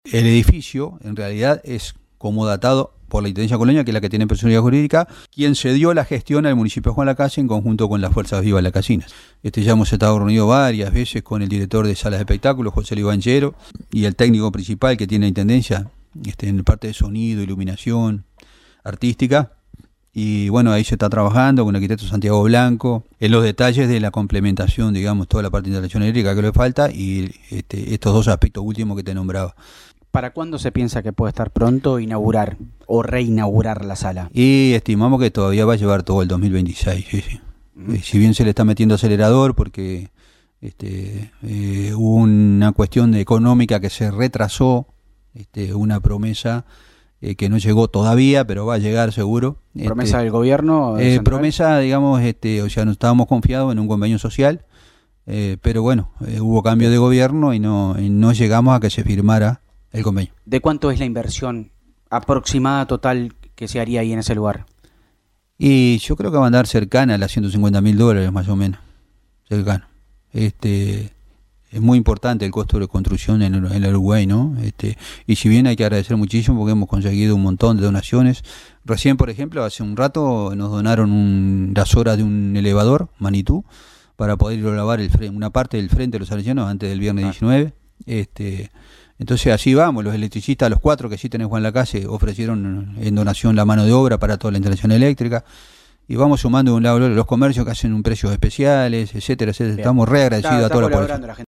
Sobre la importancia de esta obra y el alcance del proyecto, se refirió el alcalde de Juan Lacaze, Darío Brugman.